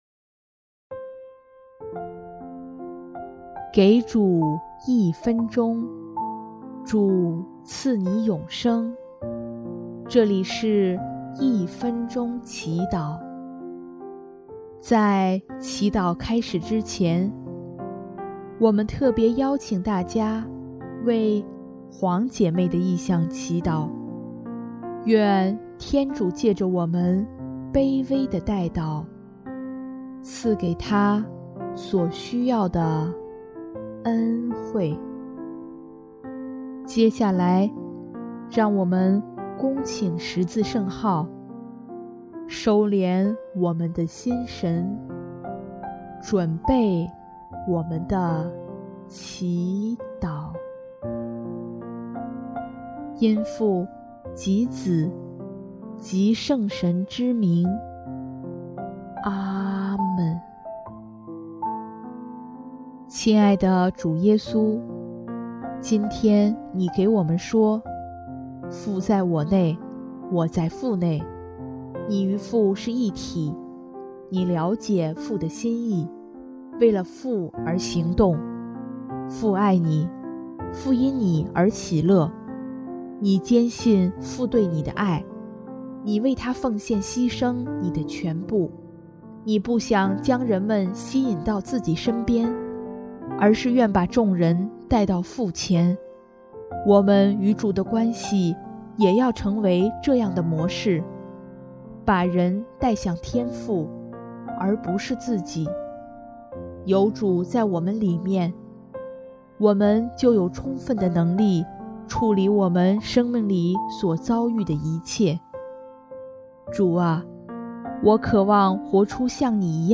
【一分钟祈祷】|3月22日 “父在我内，我在父内”